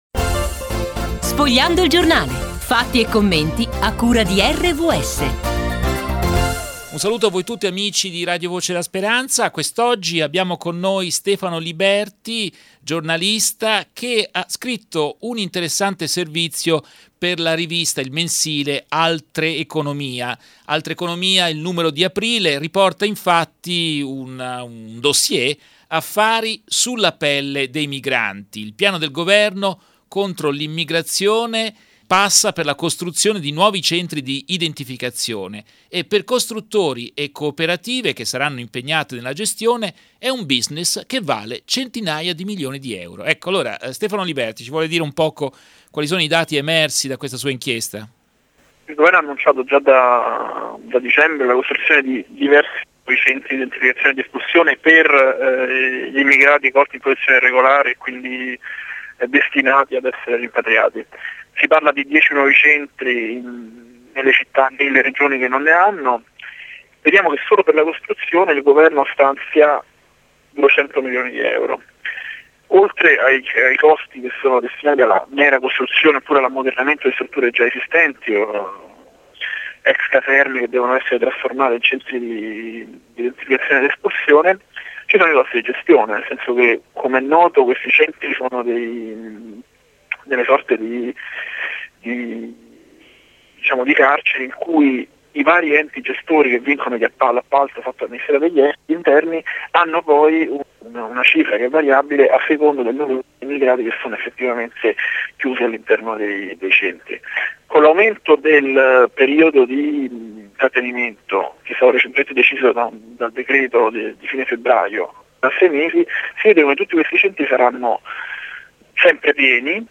Radio Podcast
Intervista